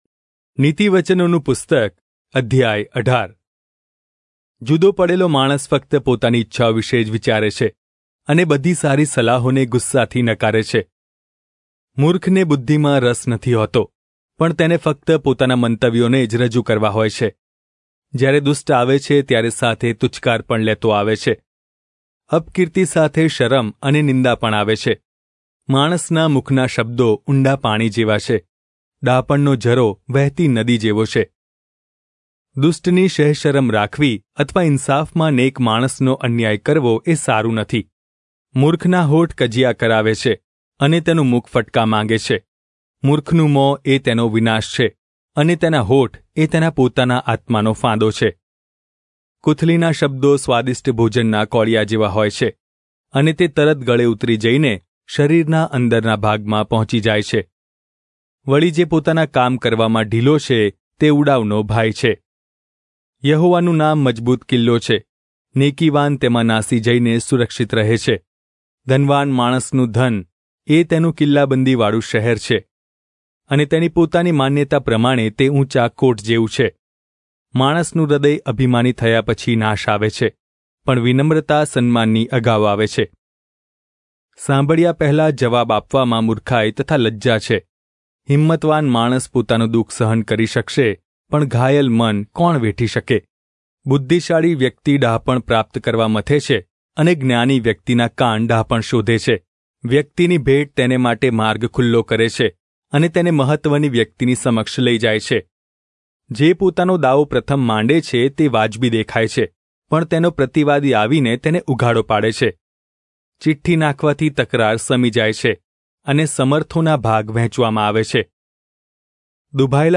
Gujarati Audio Bible - Proverbs 18 in Irvgu bible version